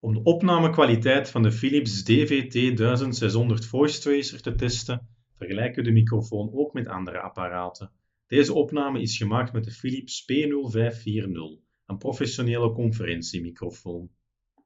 Audio fragment 4 (Philips Smartmeeting PSE0540)